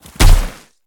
Sfx_creature_snowstalker_run_os_04.ogg